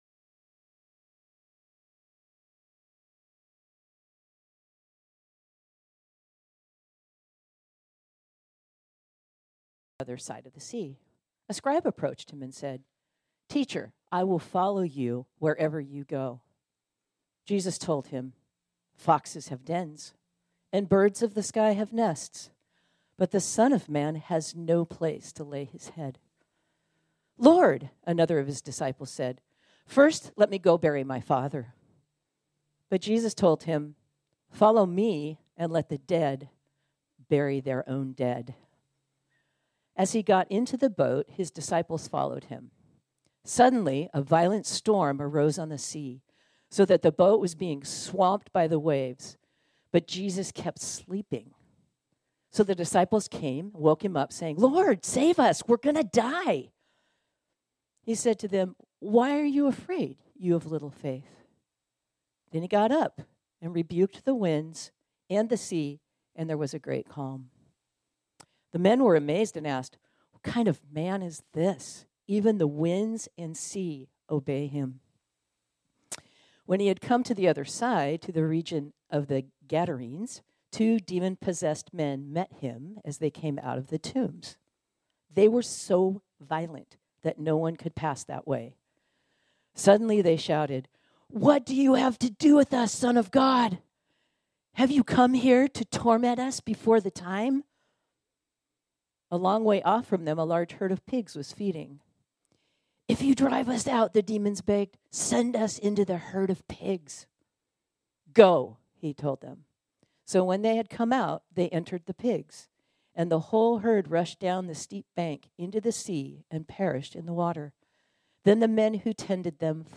This sermon was originally preached on Sunday, April 14, 2024.